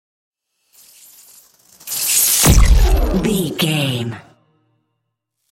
Whoosh to hit sci fi disappear debris
Sound Effects
futuristic
intense
woosh to hit